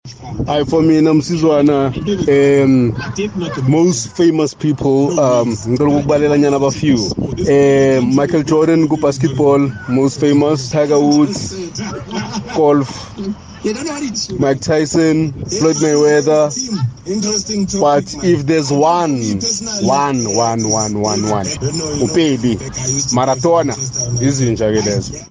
Kaya Drive listeners picked their most famous sporting personalities: